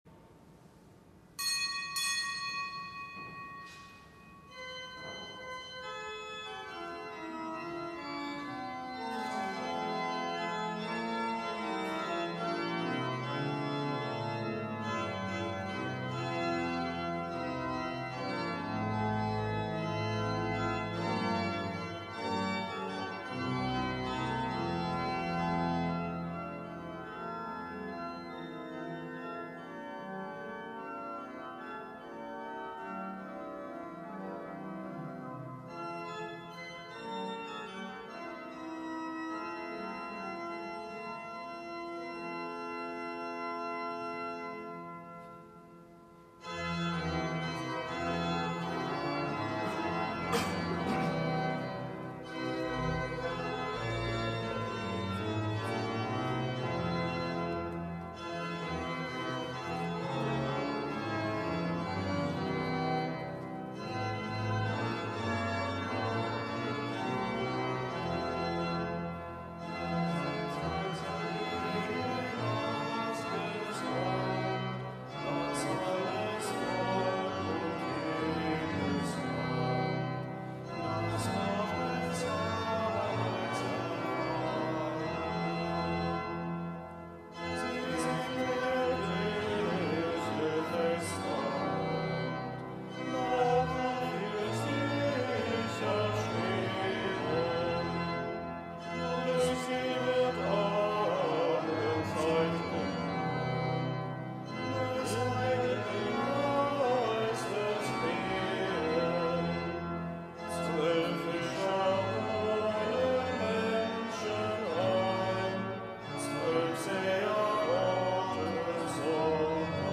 Kapitelsmesse aus dem Kölner Dom am Fest des Heiligen Matthias